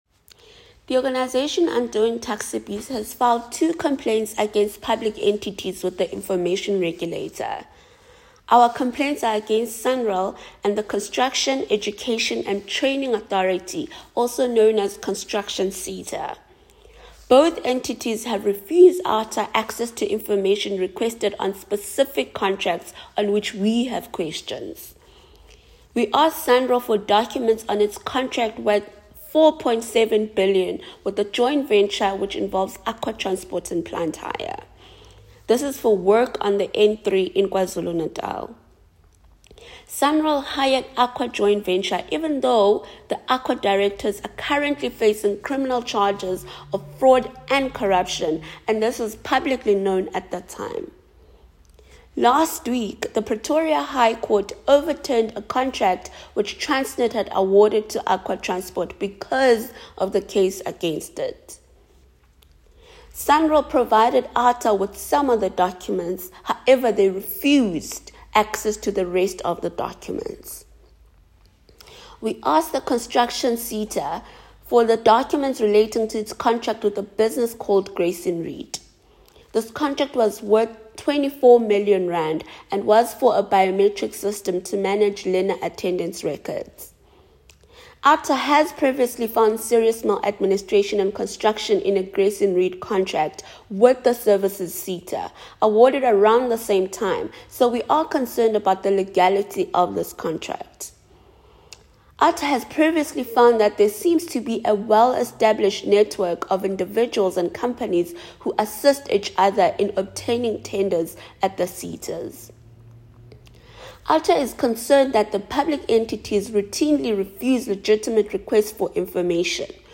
A voicenote with comment